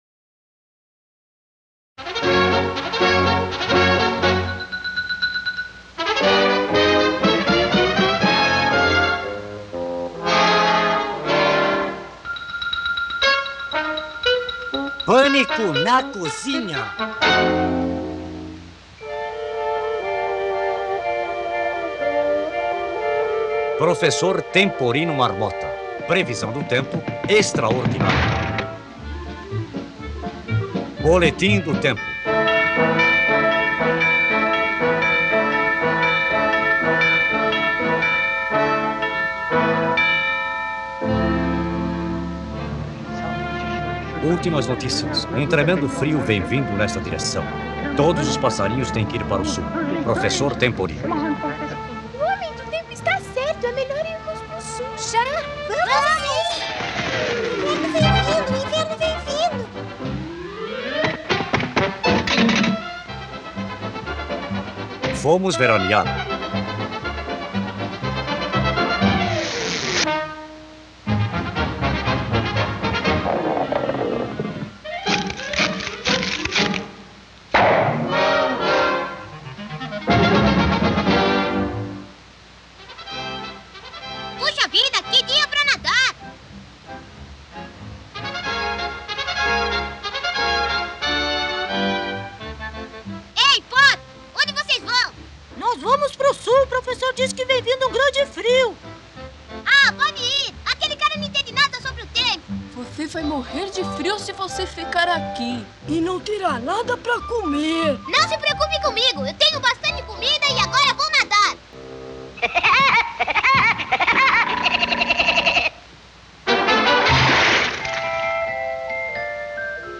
com M&E original